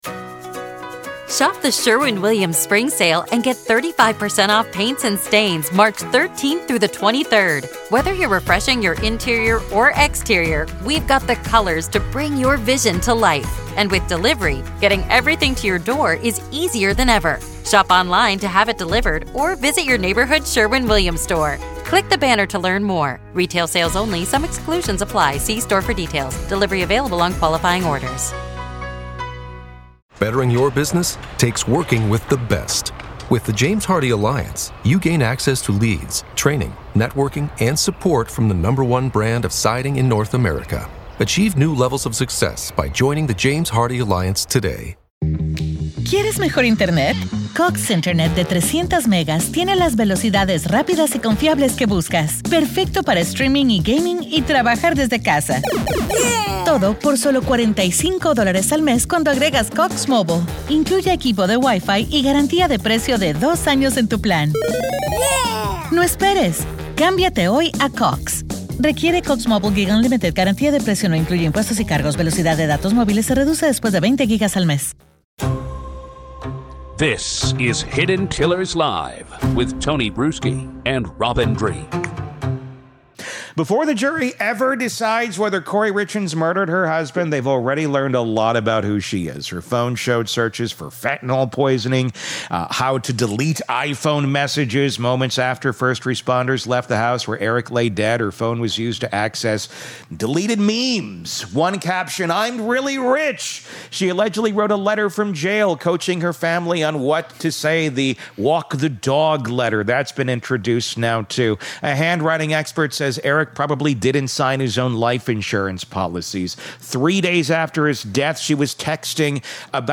This is a live conversation and your questions matter.